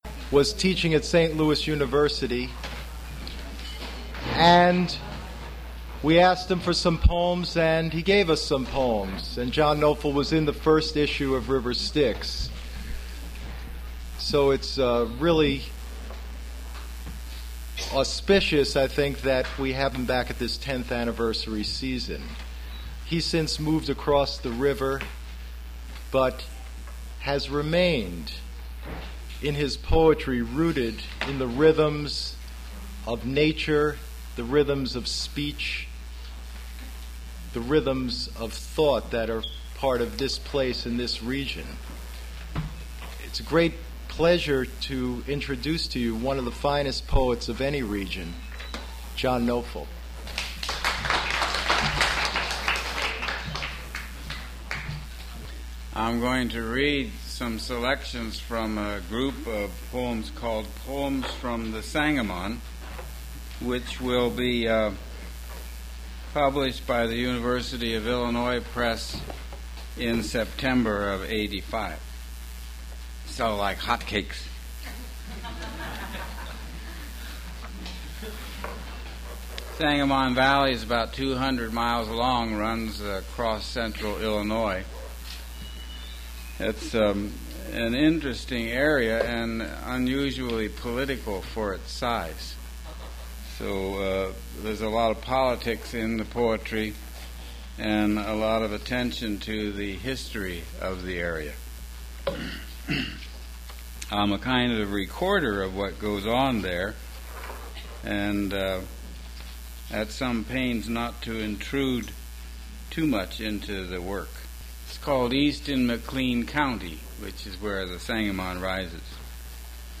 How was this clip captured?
Recording starts mid-sentence; there is a buzzing at the background of the recording